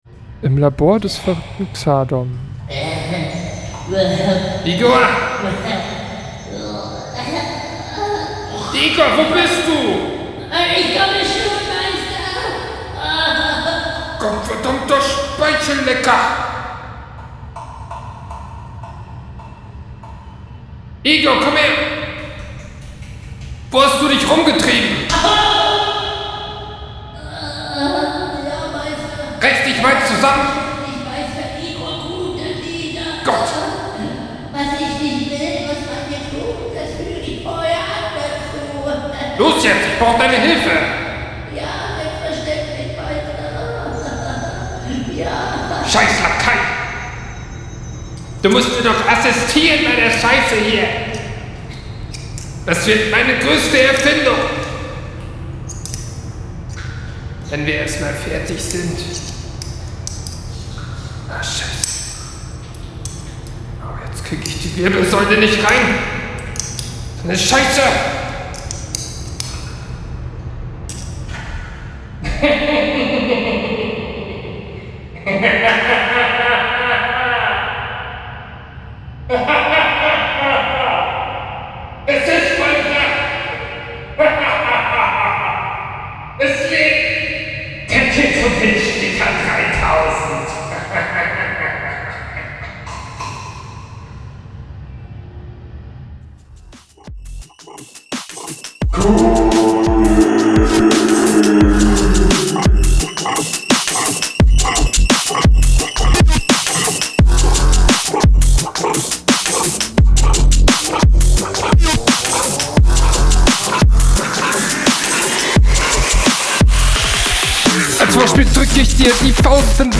Intro viel zu lange, musste skippen.
intro zu lang und unnötig wie ich finde, rap ist auch sehr abgehackt, schau das …